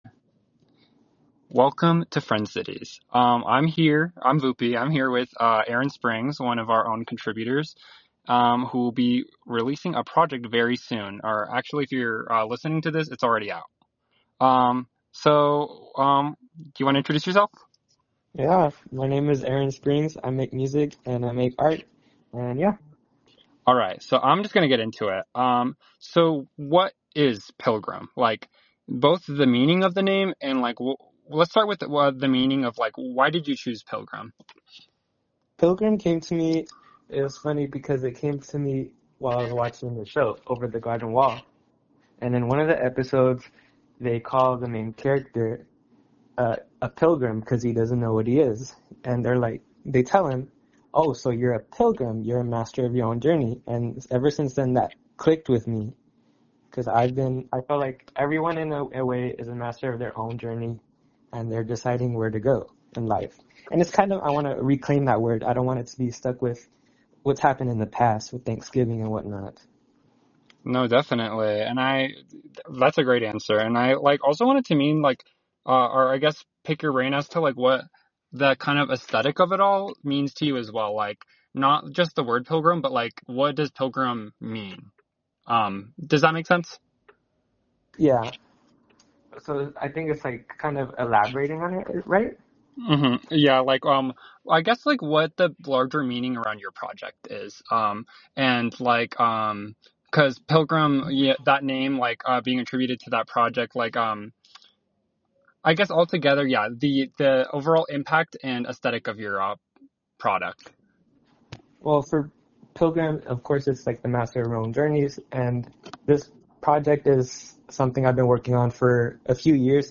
THE INTERVIEW
unedited phonecall interview